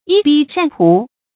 一抔黄土 yī póu huáng tǔ 成语解释 一抔：一捧。